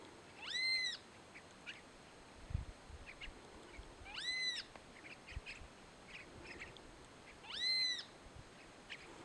Great Kiskadee (Pitangus sulphuratus)
Life Stage: Adult
Country: Argentina
Location or protected area: Santa María
Condition: Wild
Certainty: Recorded vocal
benteveo.mp3